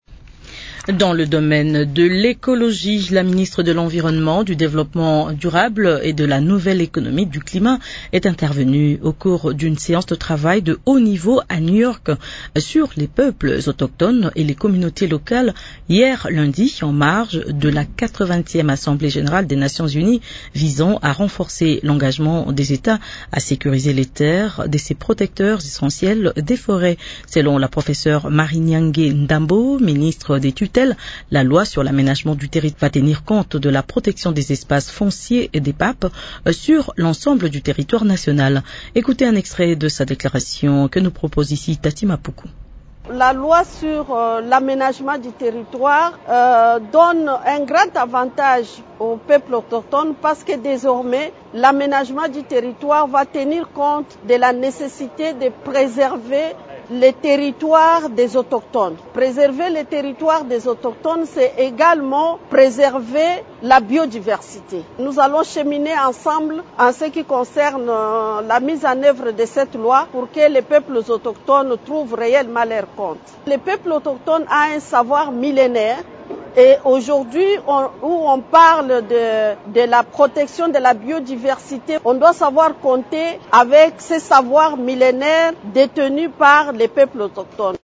Selon la professeure Marie Nyange Ndambo, ministre de tutelle, la loi sur l’aménagement du territoire en vigueur tient compte de la protection des espaces fonciers des PAP sur l’ensemble du territoire national.